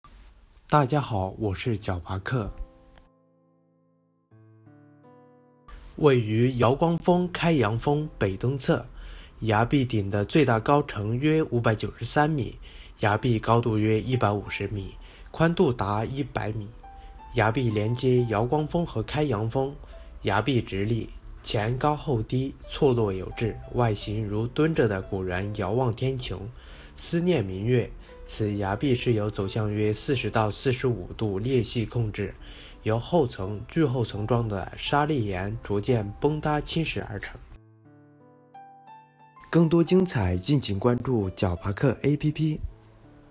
金猴赤壁----- 哥就是稳 解说词: 位于摇光峰（仙女峰）、开阳峰（双剑峰）北东侧，崖壁顶的最大高程约593m，崖壁高度约150m，宽度达100m。